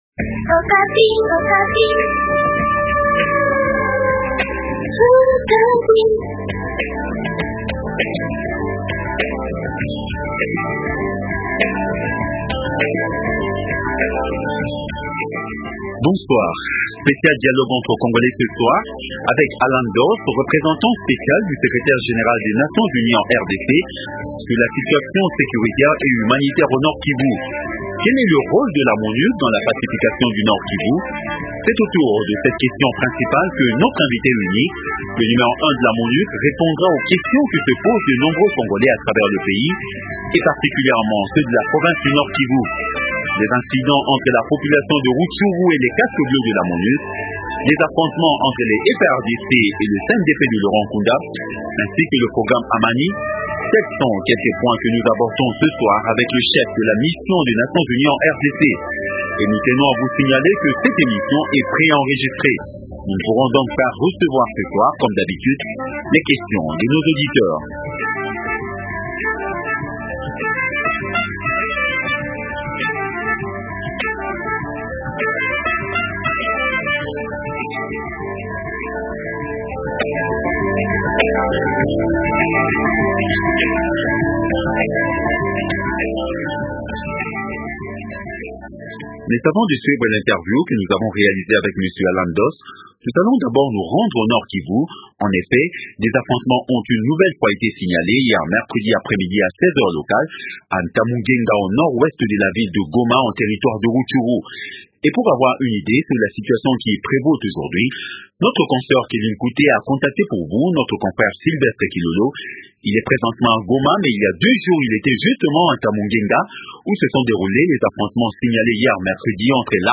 Spécial dialogue entre congolais avec Alan DOSS, représentant spécial du secrétaire général des nations unies en RDC sur la situation sécuritaire et humanitaire au Nord Kivu. Quel est le rôle de la Monuc dans la pacification du Nord Kivu ?